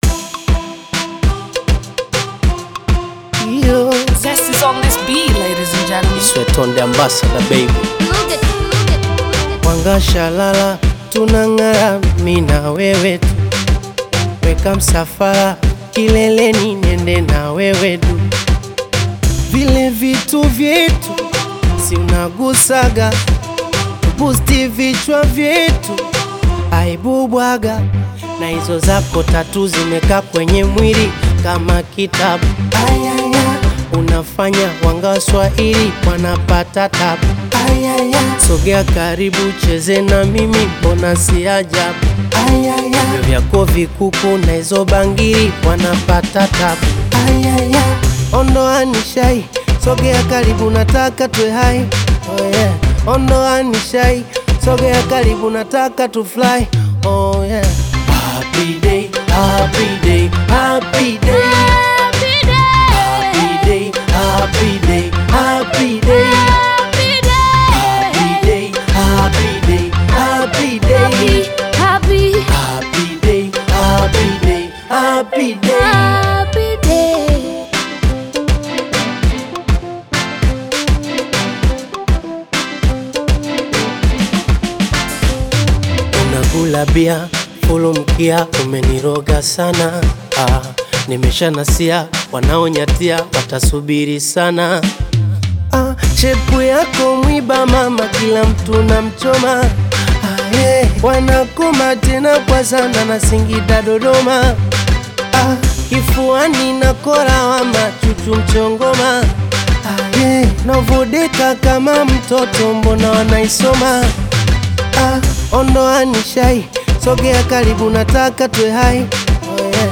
AudioBongo fleva
upbeat Bongo-Flava/Afro-Pop single
danceable production